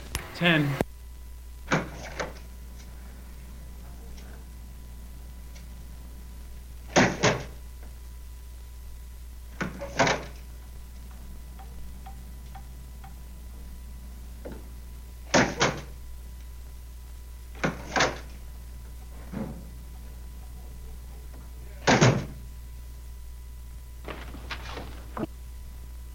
复古钟声和蜂鸣器 " G2006大本钟
描述：伦敦的大本钟钟声。深沉，混响的旋律编钟。结尾有7个单音符号。 这些是20世纪30年代和20世纪30年代原始硝酸盐光学好莱坞声音效果的高质量副本。 40年代，在20世纪70年代早期转移到全轨磁带。我已将它们数字化以便保存，但它们尚未恢复并且有一些噪音。
Tag: 编钟 钟表 眼镜 复古